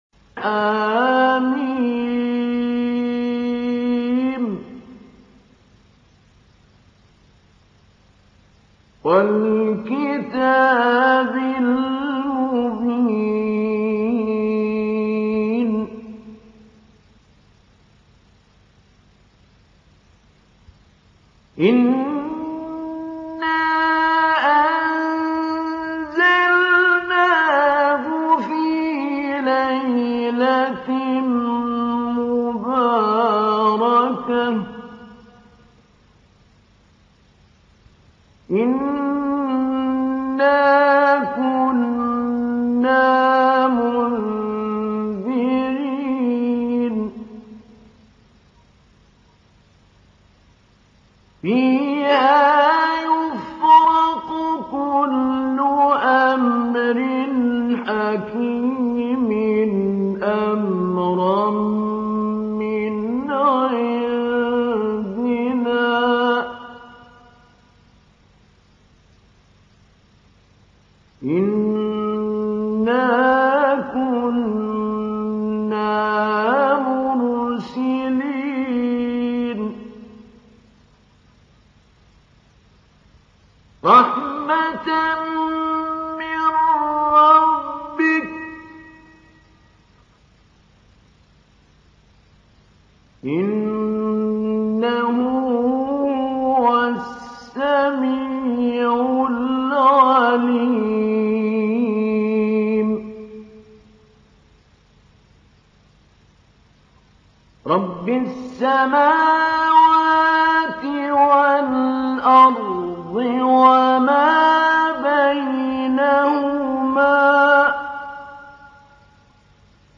سورة الدخان | القارئ محمود علي البنا